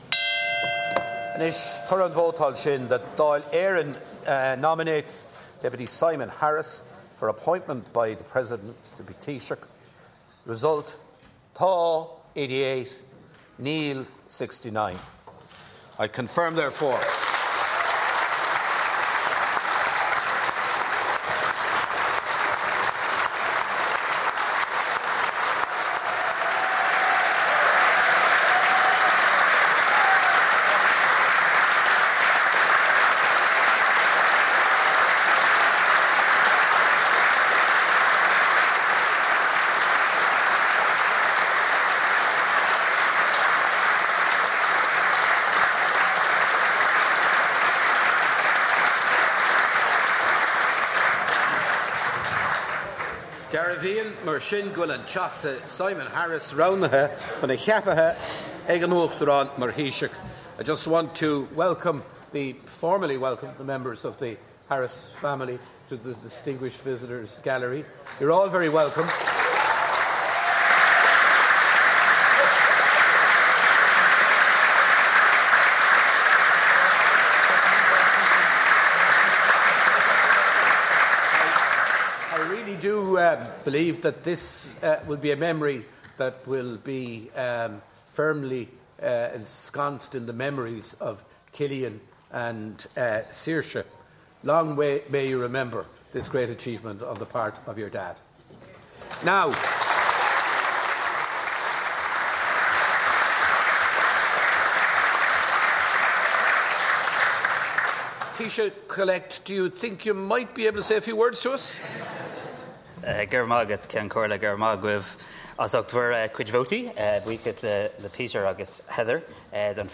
harris-welcoming-speech.mp3